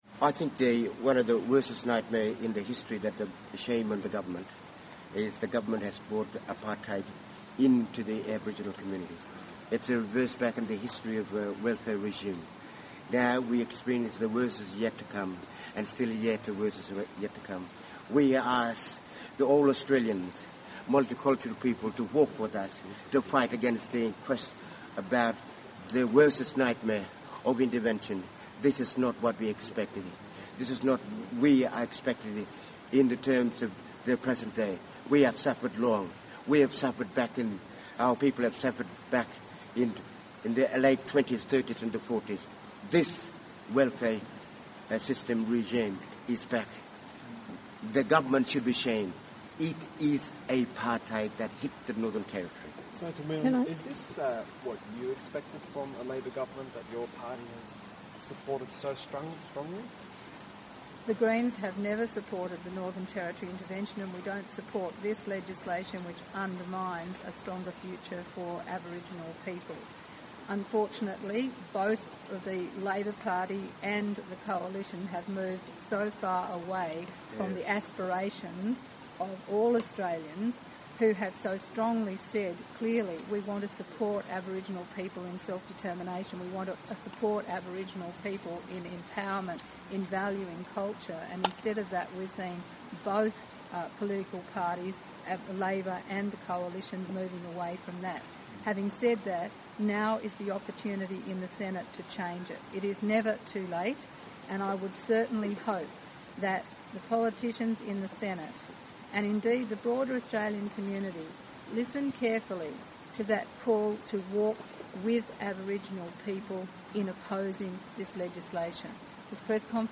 Questions & Answers - A coalition of Aboriginal, church and community leaders came together in Canberra on the 18th June 2012 to oppose the Government's proposed legislation to extend the Northern Territory intervention for Aboriginal communities.